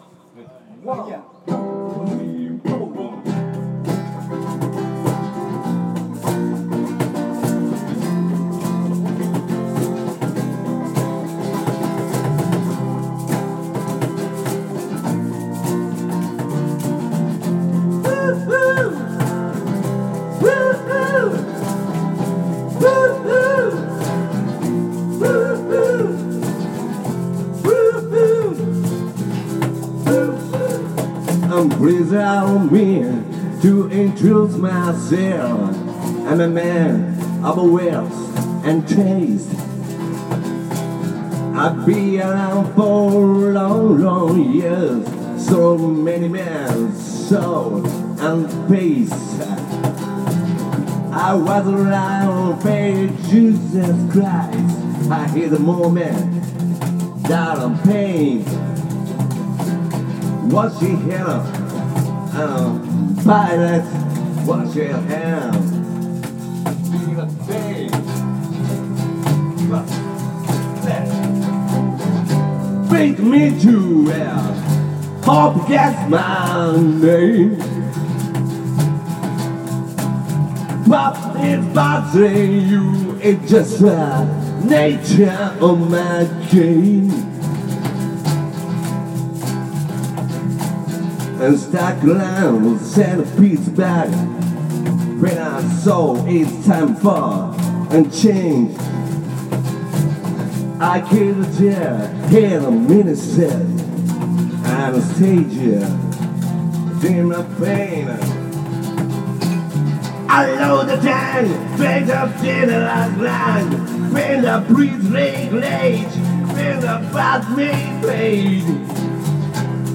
vo gt